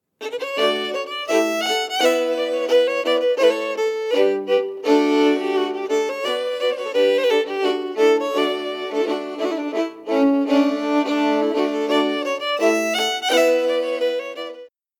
Duo and Trio harmony lines